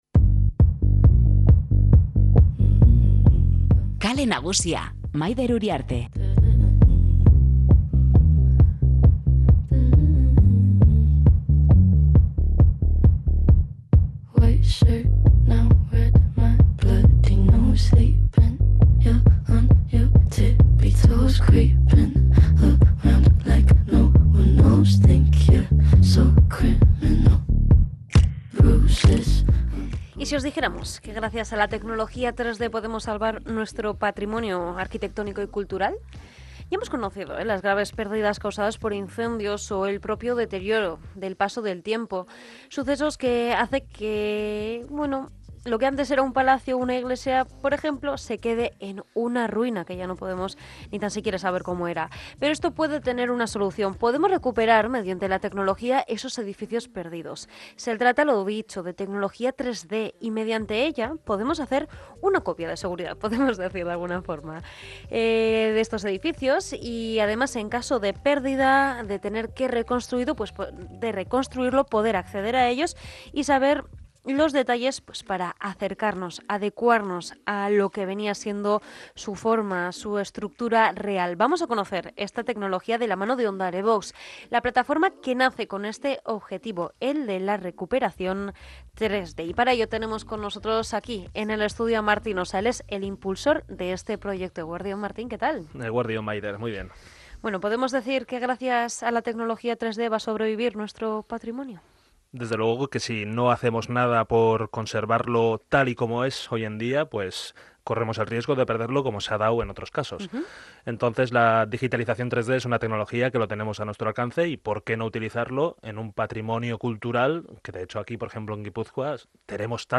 Entrevista OndareBox Onda Vasca | ONDAREBOX